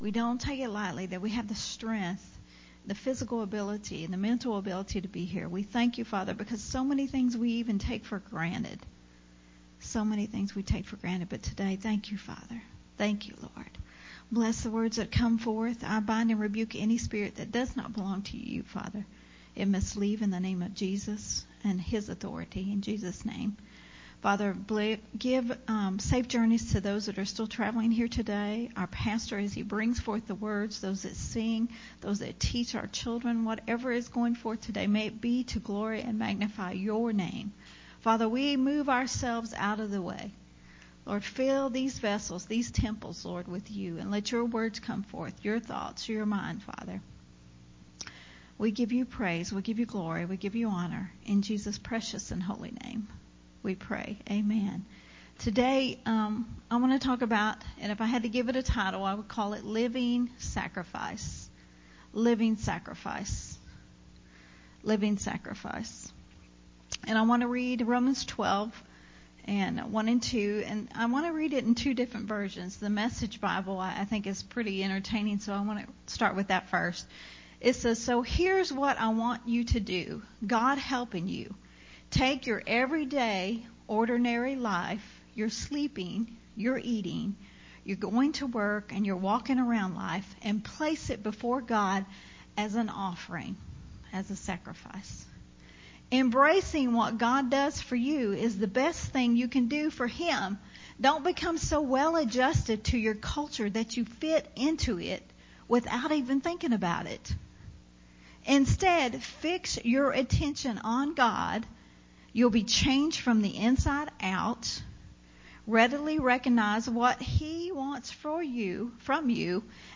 Sunday Morning Refreshing